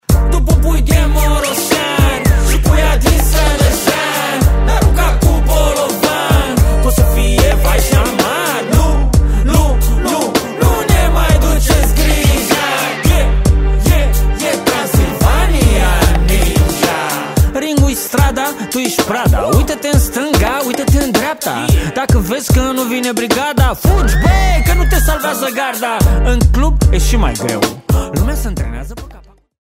Categorie: Romaneasca